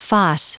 Prononciation du mot foss en anglais (fichier audio)
Prononciation du mot : foss